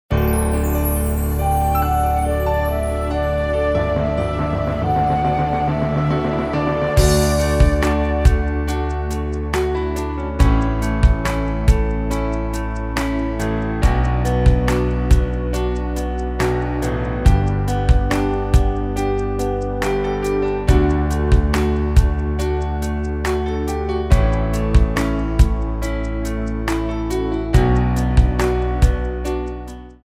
Rubrika: Vánoční písně, koledy
Karaoke